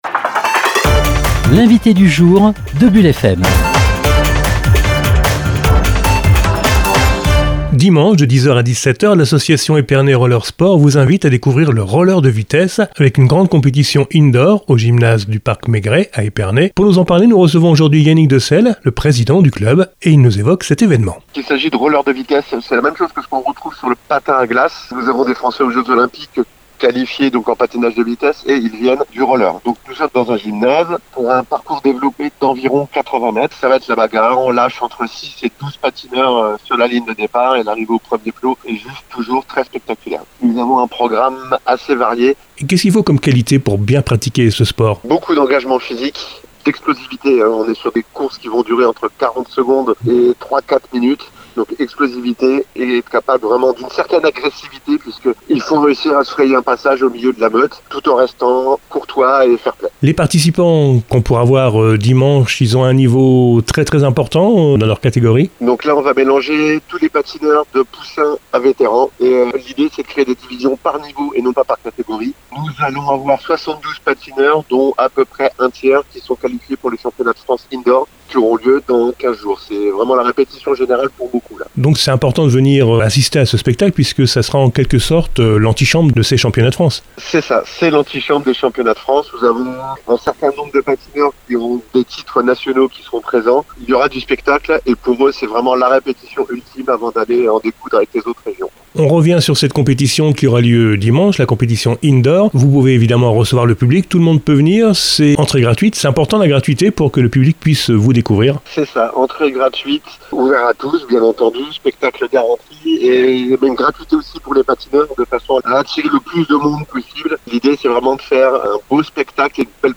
interview-bulle.mp3